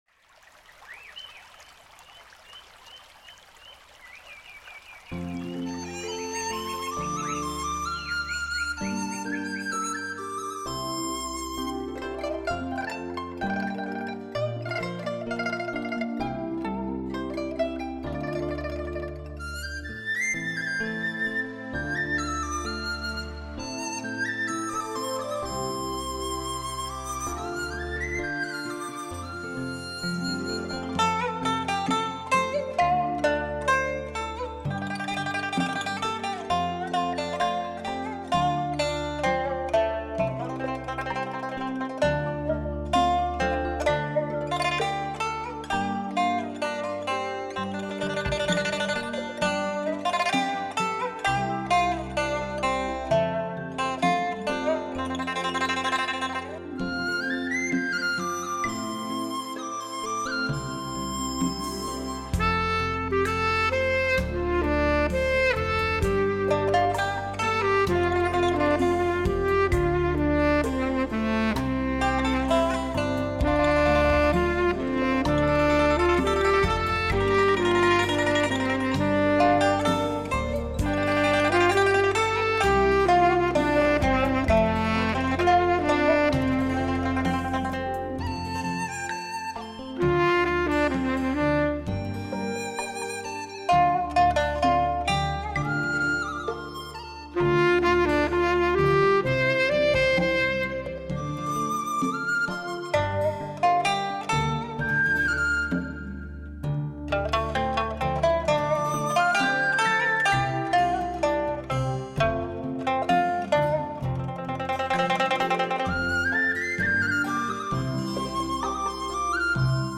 带来超乎想像震撼性的6.1环绕新体验。